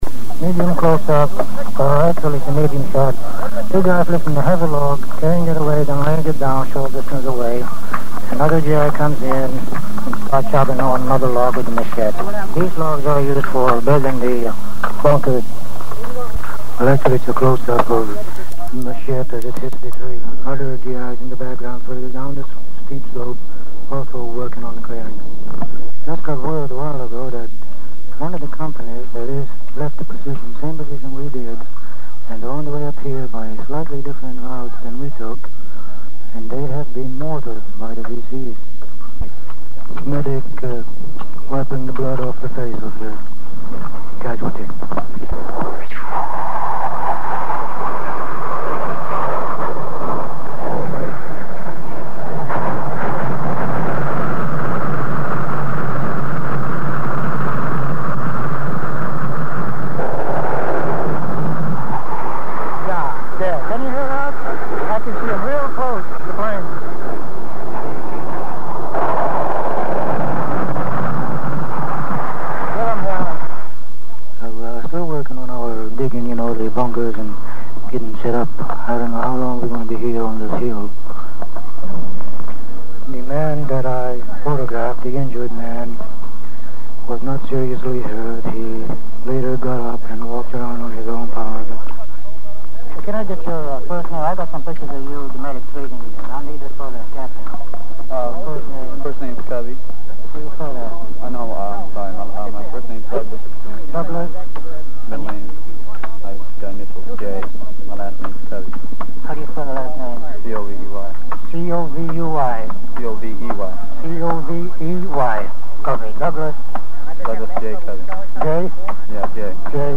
The original tape runs about an hour in length.